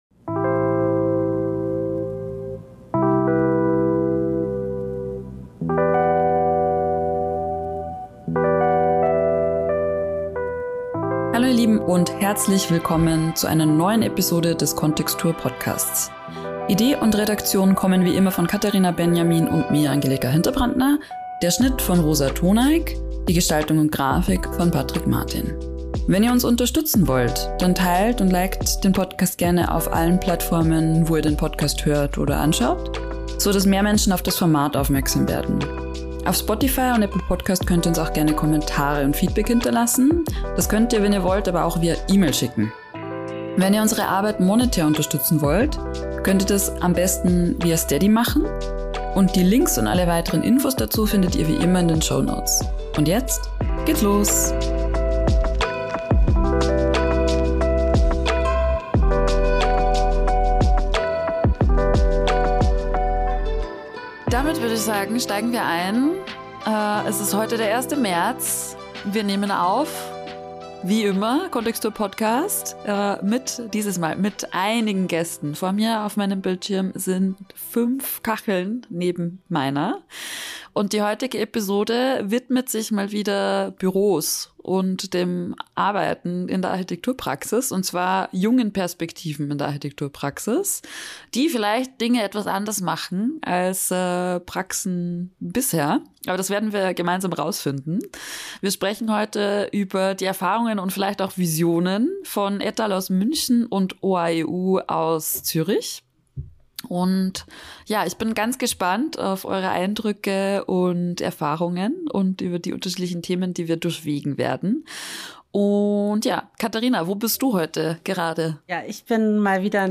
Diesmal haben wir etal. aus München und OAEU aus Zürich zum Gespräch eingeladen.